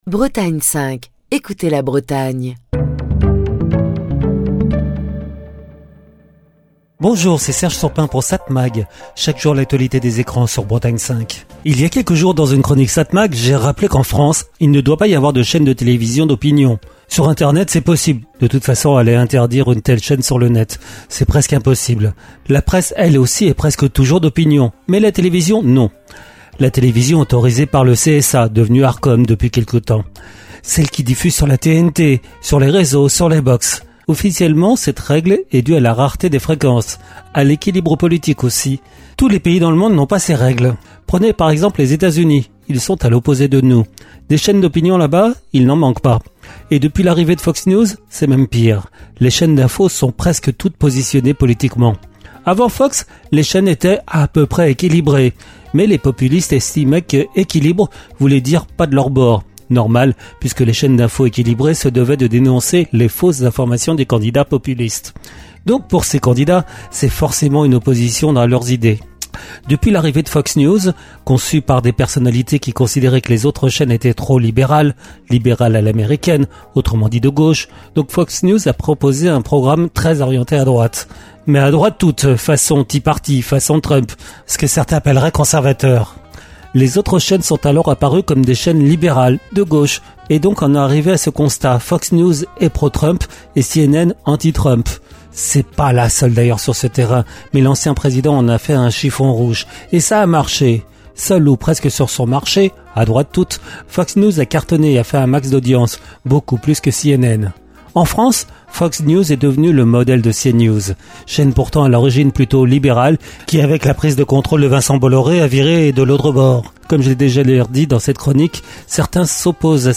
Chronique du 15 mai 2025. Les médias d'opinion influencent-ils notre société ? Ou dans quelle mesure l'influence de ces chaînes pèse sur l'opinion publique et la pluralité des médias ?